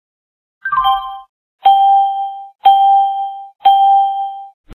beeps.mp3 Rules Contacts Language English Русский This site will not work without javascript!